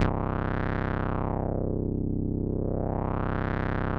Index of /90_sSampleCDs/Trance_Explosion_Vol1/Instrument Multi-samples/Wasp Dark Lead
C1_wasp_dark_lead.wav